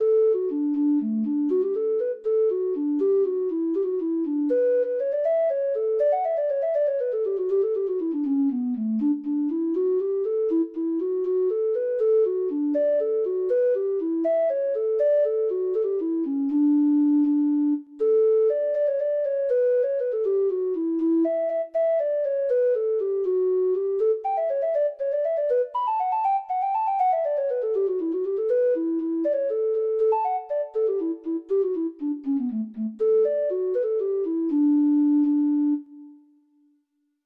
Traditional Trad. Planxty O'Neill (Irish Folk Song) (Ireland) Treble Clef Instrument version
Traditional Music of unknown author.
Irish